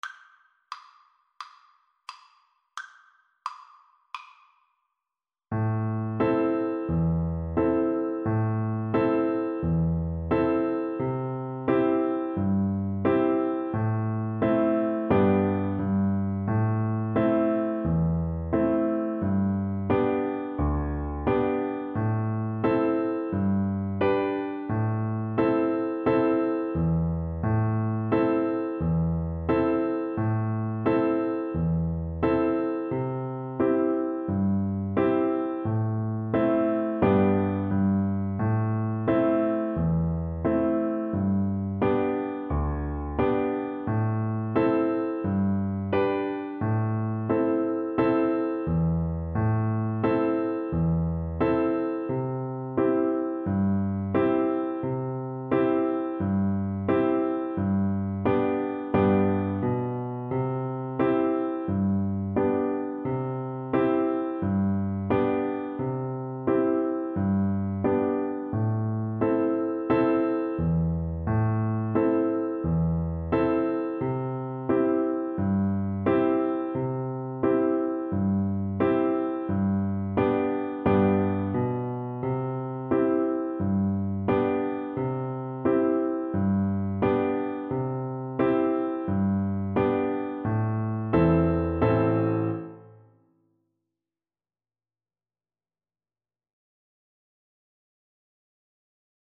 Play (or use space bar on your keyboard) Pause Music Playalong - Piano Accompaniment Playalong Band Accompaniment not yet available transpose reset tempo print settings full screen
Allegro (View more music marked Allegro)
G major (Sounding Pitch) (View more G major Music for Viola )
4/4 (View more 4/4 Music)